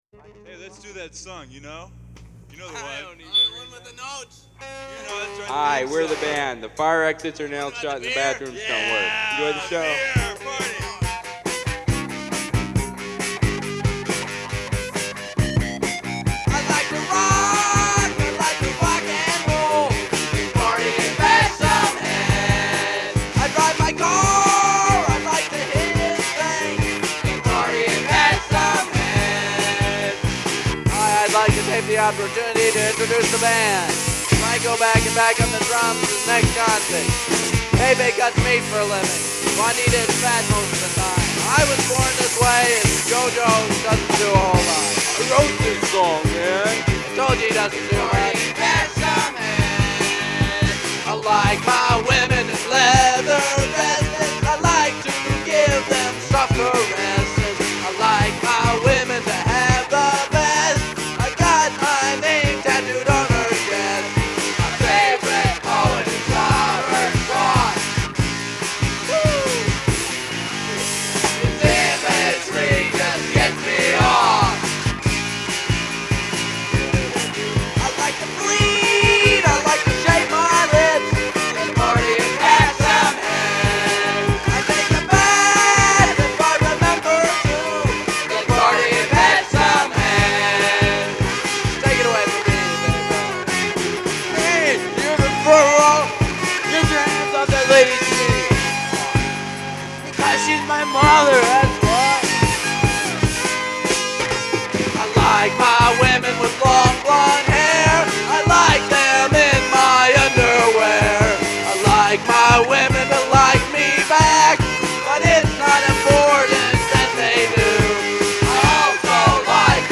It’s a rock song about rocking
drums
backup vocals